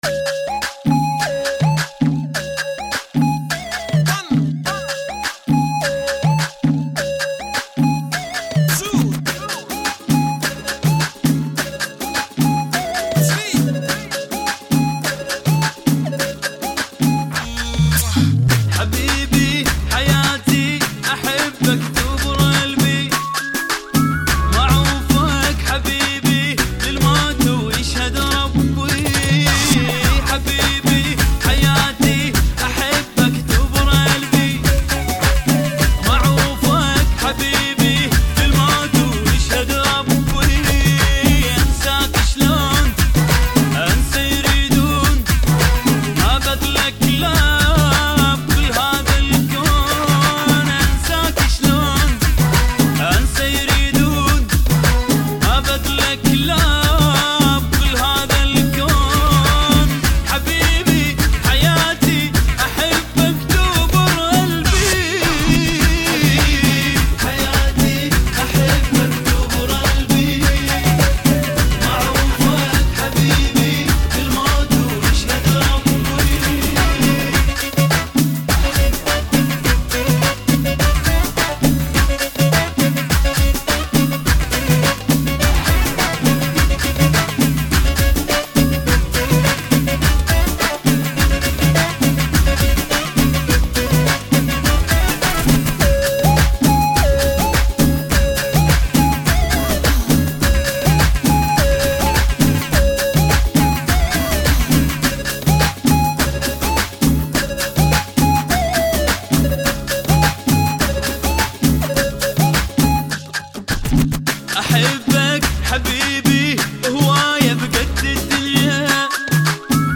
(104 BPM)